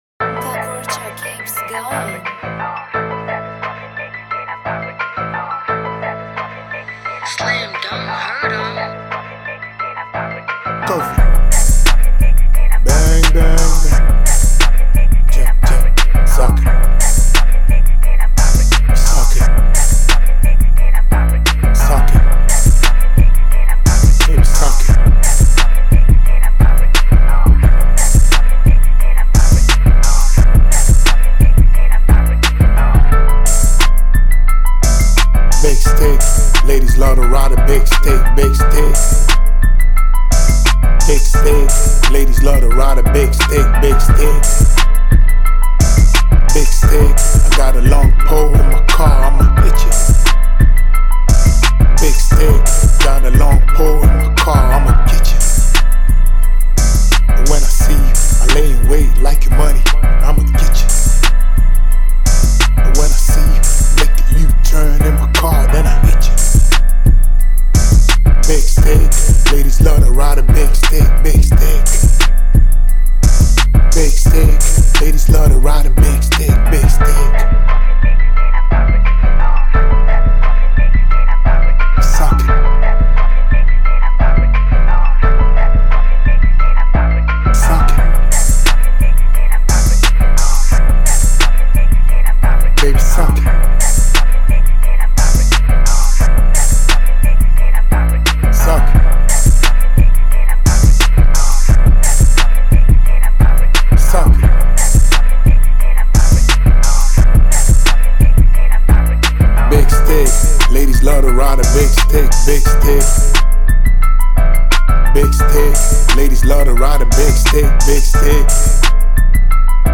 summer time big speaker music
Straight party rap music and ready for strip club fun.